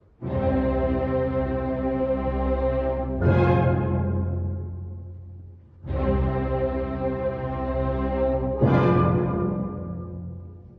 突き刺さるような鋭い序奏と主部
↑古い音源なので聴きづらいかもしれません！（以下同様）
冒頭、全員による鋭いC音と短和音が突き刺さるように鳴り響きます。
続く主部はアレグロ・コン・ブリオ、調性はハ短調。
切れ目のない推進力によって、復讐に燃える主人公の激しい心情が立ち上がってくるかのようです。